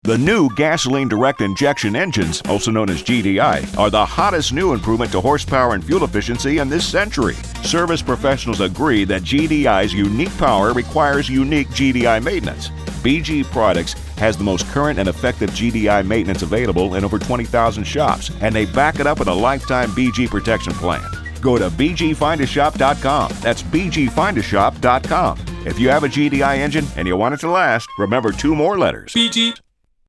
If you listen to SiriusXM you are hearing this new spot from BG about Gasoline Direct Injection (GDI) Maintenance Services.